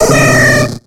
Cri de Teddiursa dans Pokémon X et Y.